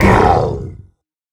latest / assets / minecraft / sounds / mob / ravager / hurt3.ogg
hurt3.ogg